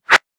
metahunt/weapon_bullet_flyby_12.wav at master
weapon_bullet_flyby_12.wav